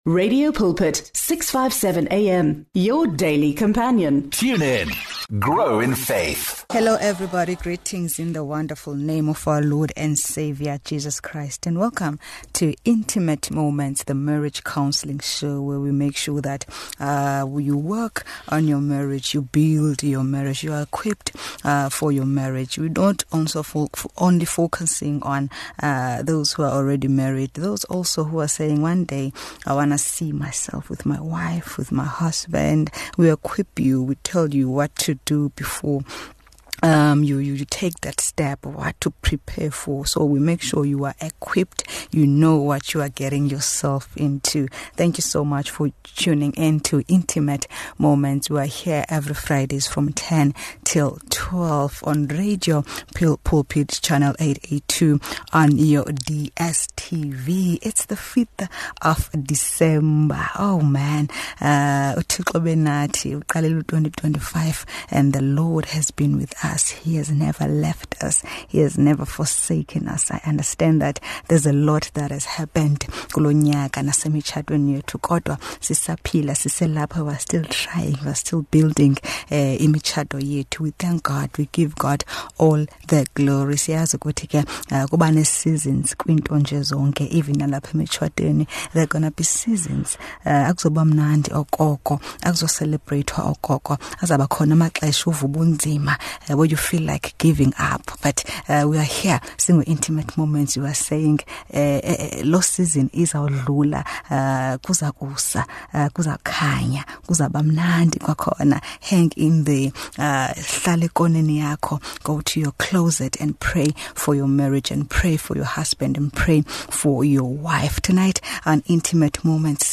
Listeners also tune in for the shows quality gospel music and compelling Christian content. With a strong focus on relationships, our guests and the topics we tackle aim at creating an intimate space where both the listener and guests can openly share their struggles and victories.
Intimate Moments on Radio Pulpit is an informative and spiritually uplifting late-night mood programme.